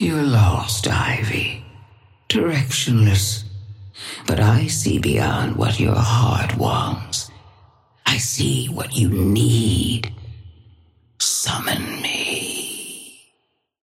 Patron_female_ally_tengu_start_01.mp3